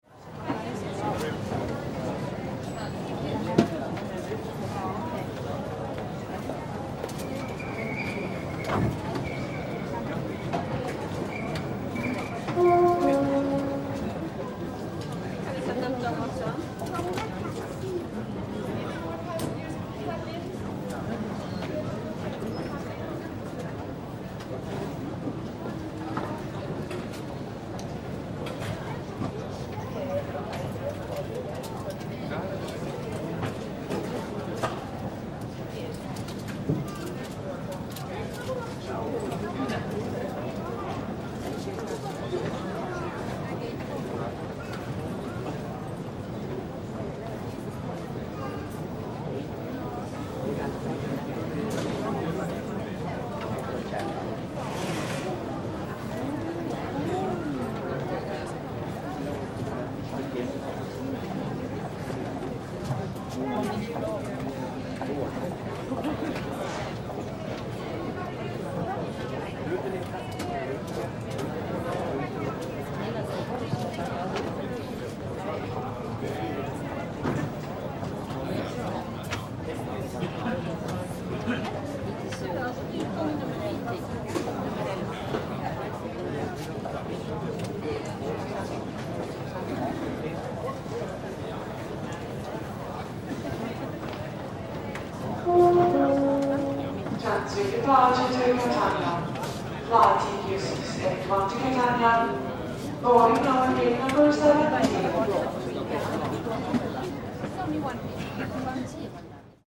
Окунитесь в атмосферу аэропорта с коллекцией звуков объявлений и фоновых шумов.
Толпа у регистрации